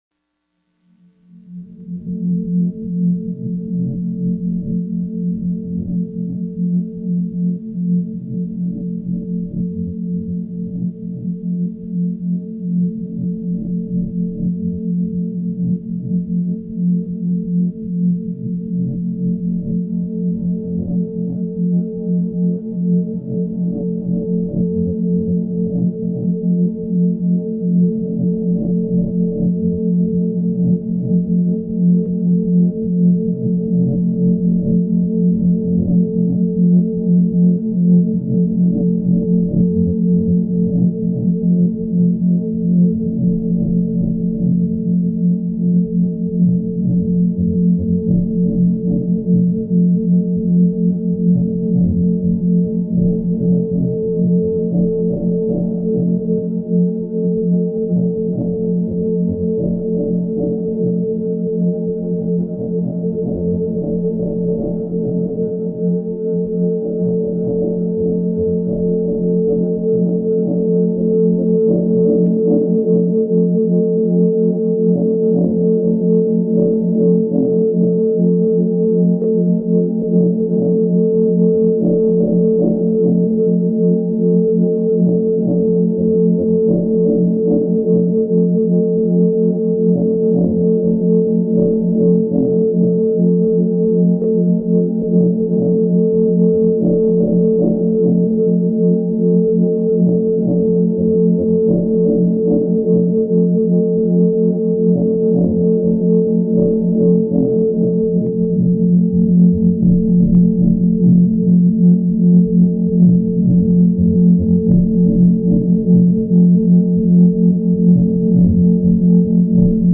soundscapes, minimal-house, and electro-beats